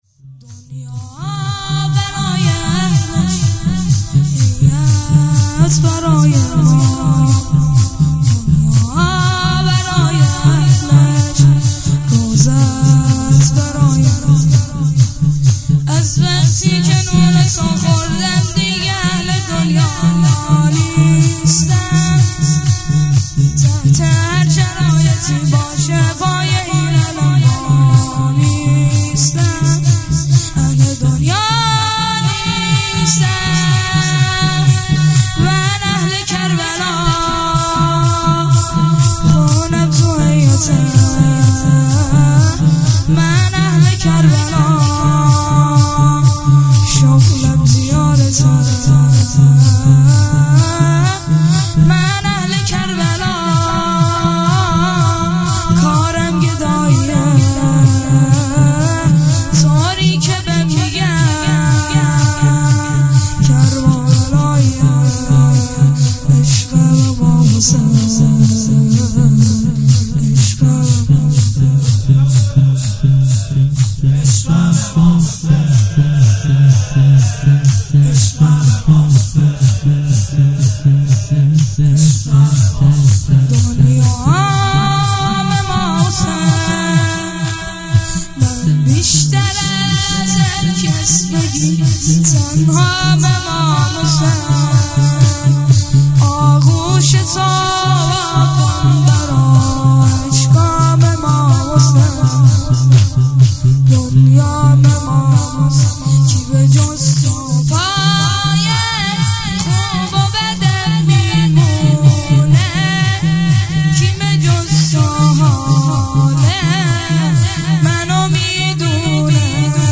هیئت هفتگی